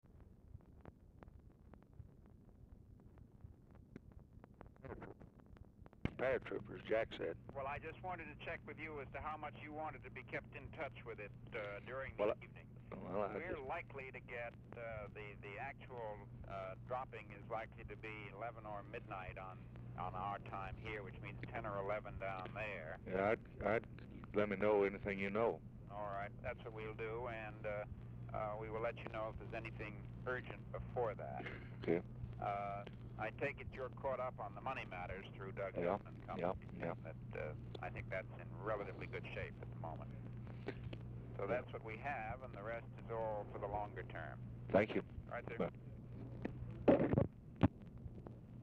Telephone conversation # 6456, sound recording, LBJ and MCGEORGE BUNDY, 11/23/1964, 3:23PM | Discover LBJ
Format Dictation belt
Location Of Speaker 1 LBJ Ranch, near Stonewall, Texas
Specific Item Type Telephone conversation